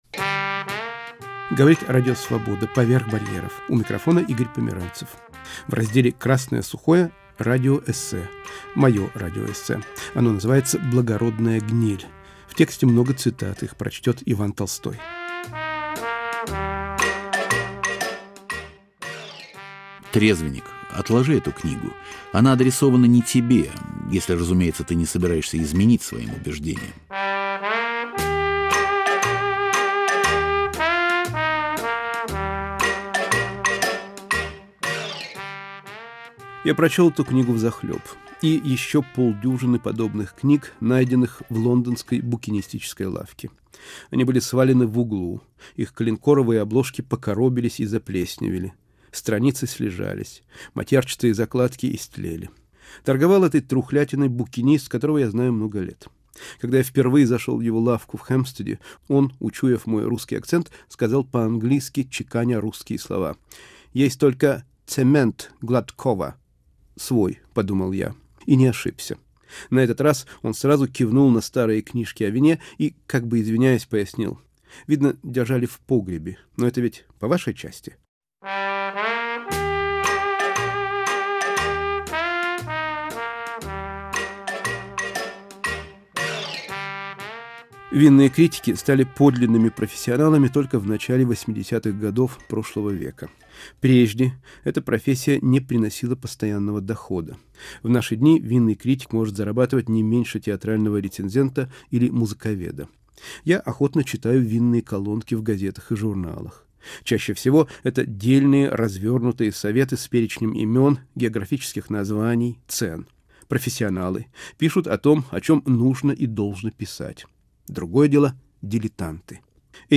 Радиоэссе «Благородная гниль»